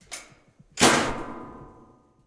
Washing Machine Door Close Sound
household